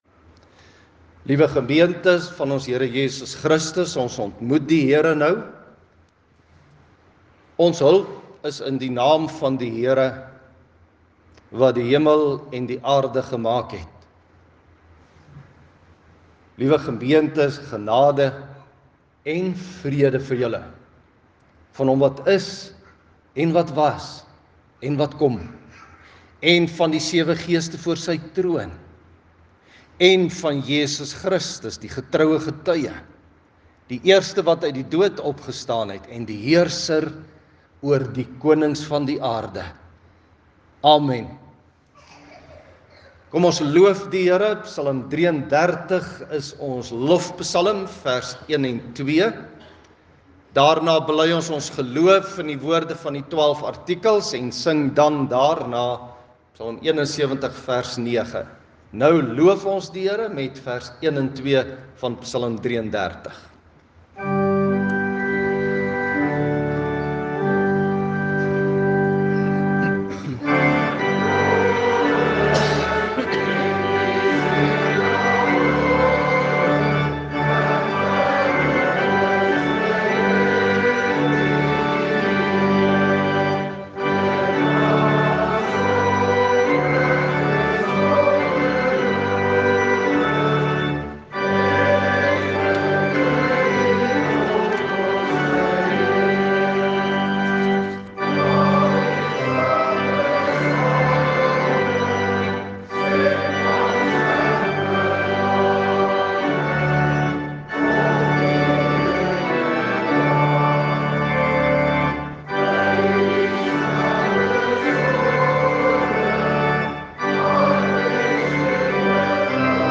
Klankbaan Luister na die preek.